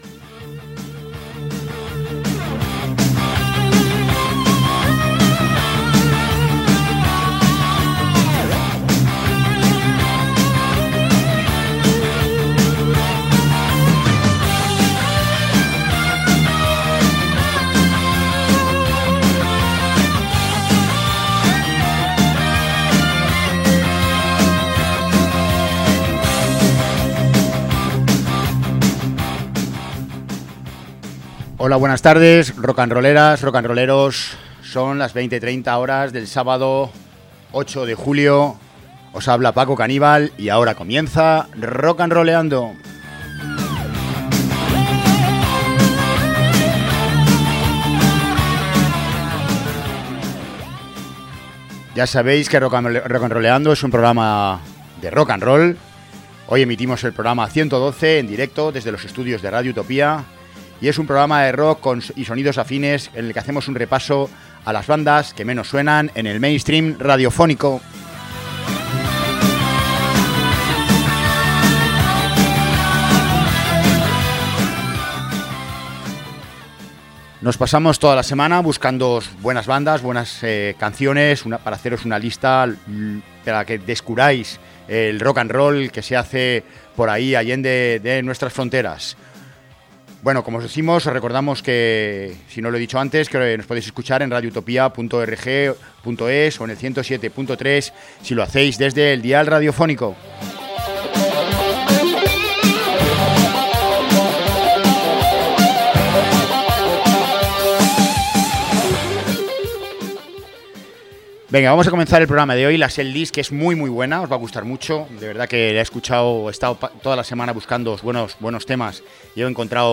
El hard rock con tintes cercanos al metal que nos presenta esta formación nos sigue gustando mucho. Con líneas de guitarras rígidas y fangosas, carreras vocales melódicas y altísimas, retorcidas en torno a la narración psicodélica. Una mezcla perfecta entre el rock sureño y el alternativo, es como su metiéramos en la misma canción a Lynyrd Skynyrd y a Tool.
La banda era un auténtico cañonazo haciendo canciones de bluegrass, hard rock con sonido muy metalero, pero con mucho deje sureño.